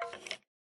mob / skeleton2